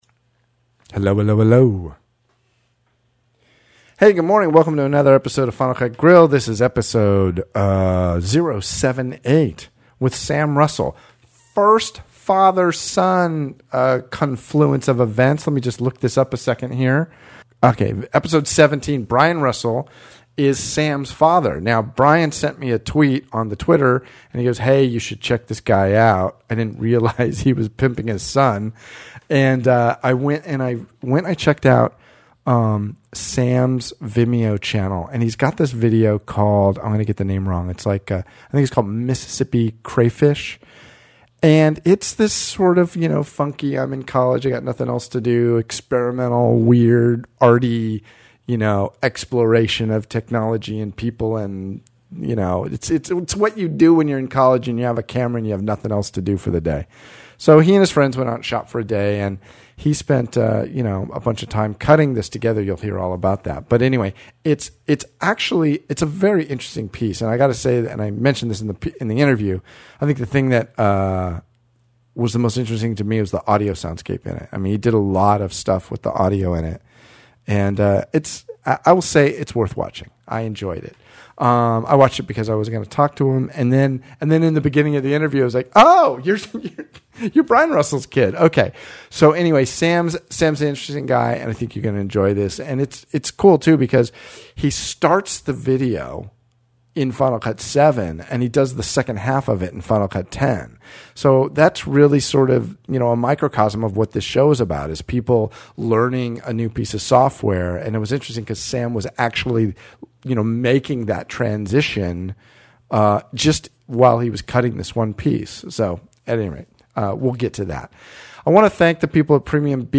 We’ll also hear from the audience via voicemail and discuss Motion vs. After Effects.